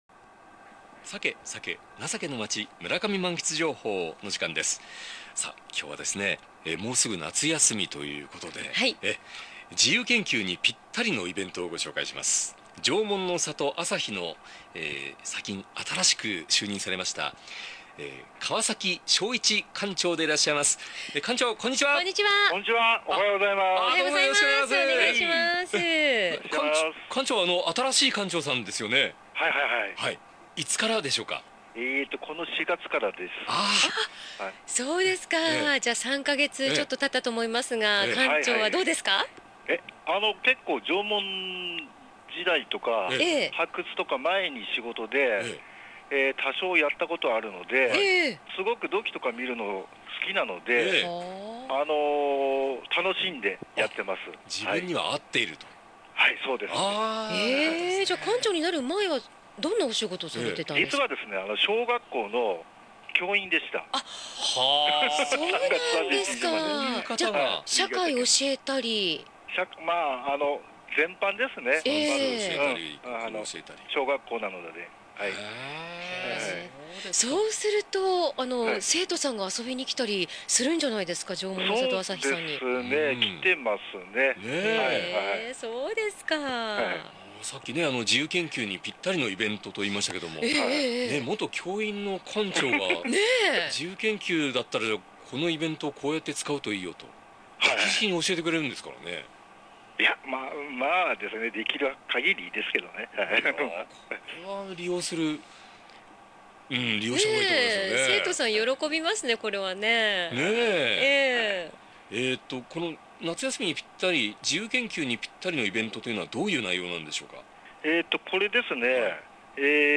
酒・鮭・人情の町　村上満喫情報（録音）」のコーナーです(^J^)